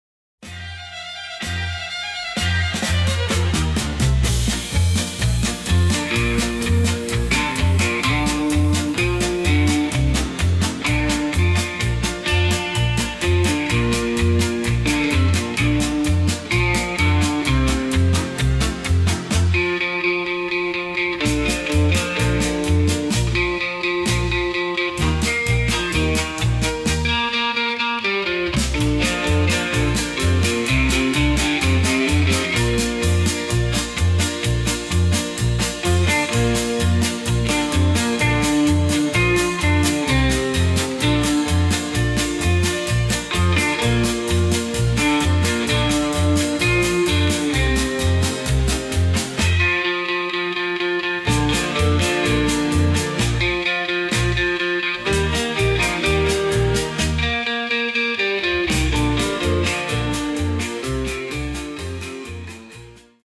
Rhythmischer Countrystyle